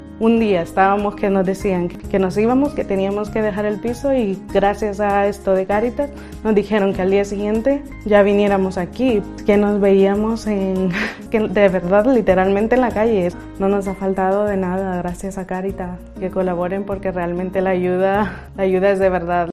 Los testimonios reales de usuarios de Cáritas La Rioja contando cómo les ha ayudado la entidad